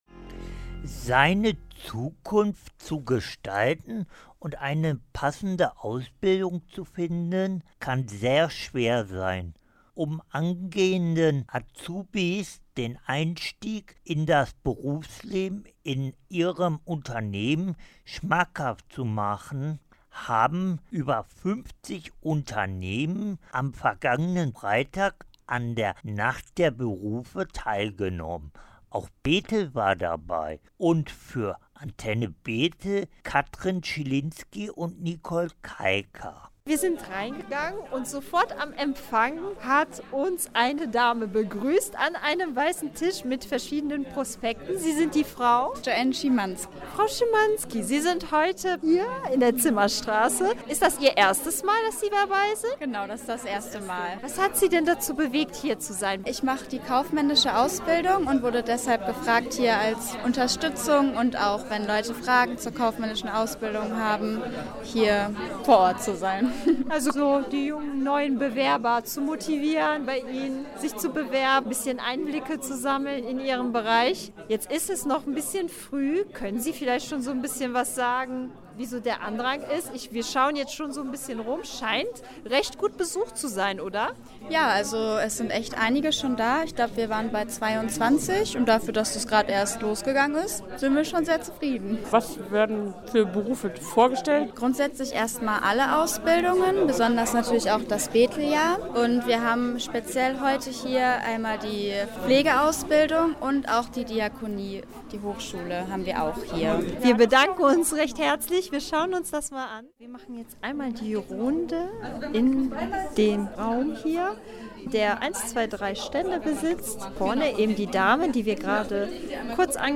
Bei beiden Veranstaltungen gehen die v. Bodelschwinghschen Stiftungen ganz bewusst auf junge Menschen zu, die im kommenden Jahr die Schule beenden und zeigen und erklären, auf welche Weise man in Bethel seine berufliche Zukunft fortsetzen kann. Hier zusammengefasst die Reportagen beider Veranstaltungen